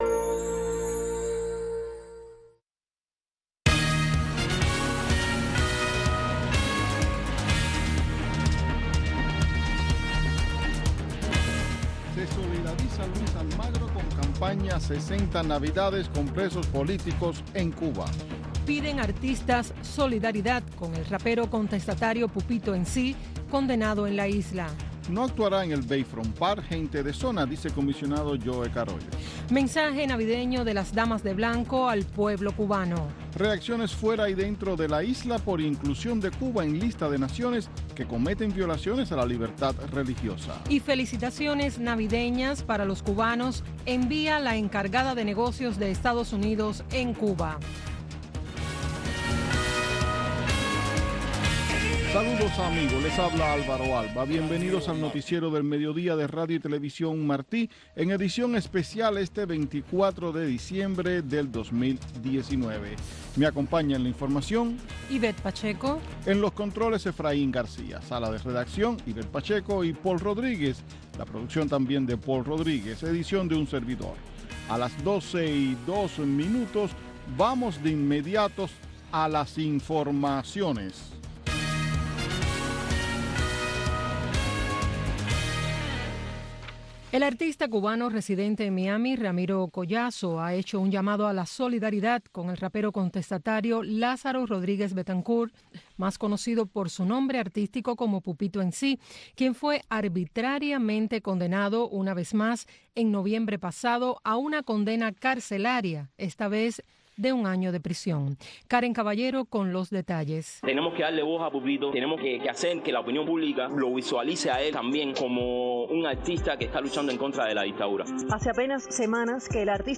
Noticiero de Radio Martí